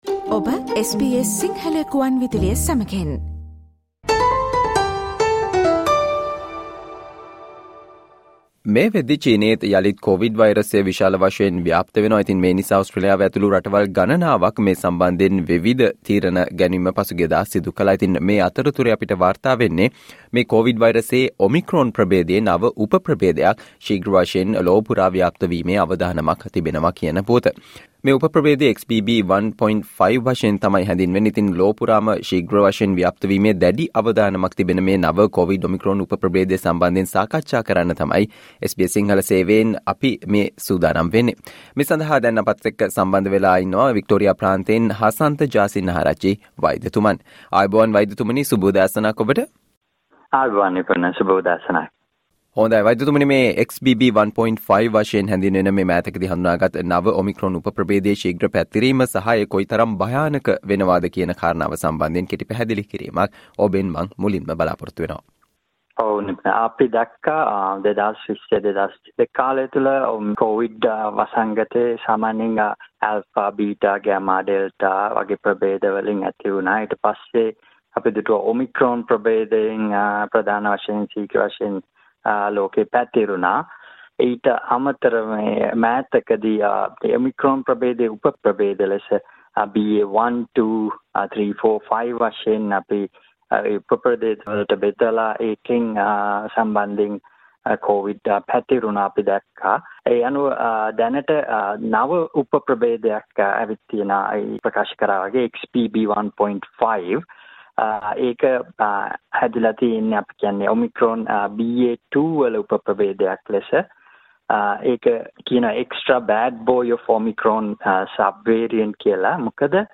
මේ වනවිට ලොව විවිධ රටවල පැතිර යන සහ ඕස්ට්‍රේලියාවේත් පැතිරීමේ ඉහල අවදානමක් තිබෙන, XBB.1.5 නව Omicron උප ප්‍රභේදයේ ශීග්‍ර පැතිරීම සහ එහි භයානකත්වය සම්බන්ධයෙන් SBS සිංහල සේවය සිදු කල සාකච්චාවට සවන් දෙන්න